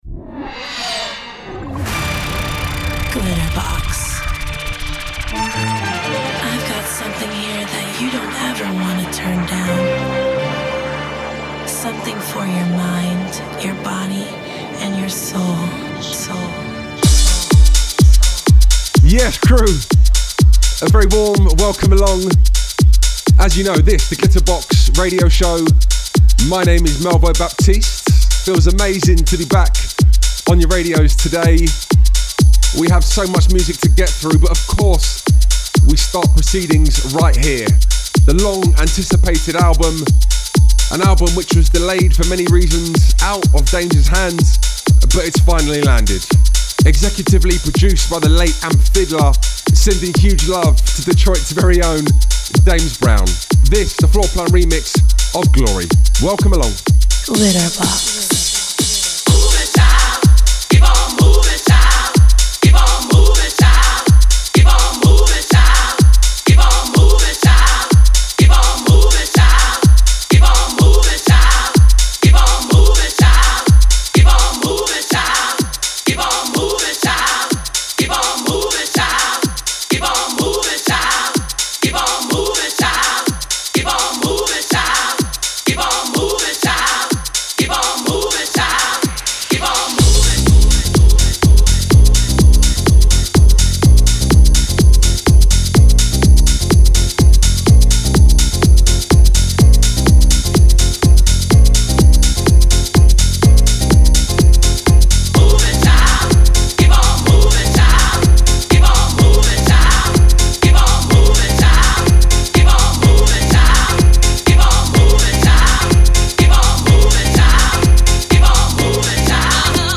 Also find other EDM Livesets, DJ Mixes and Radio Show